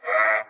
Boonga_voice_volley.wav